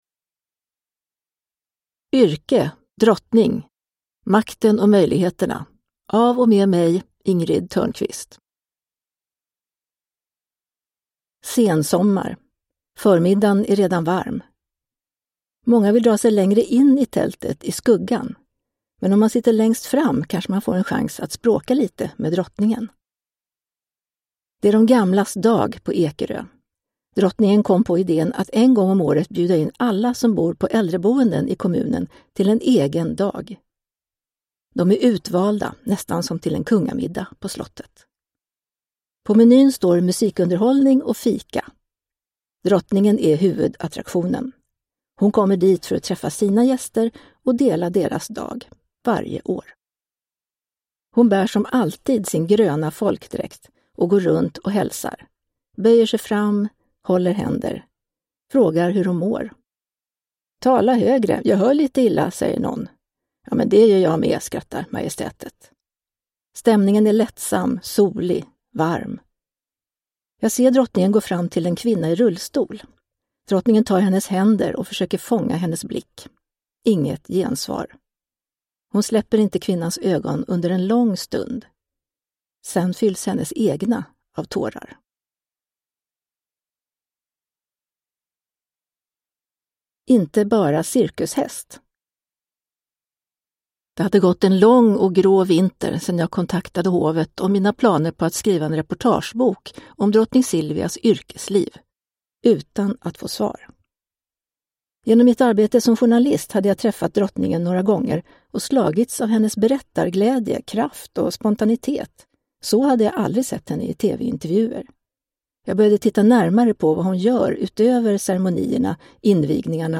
Yrke: drottning : makten och möjligheterna – Ljudbok